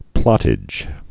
(plŏtĭj)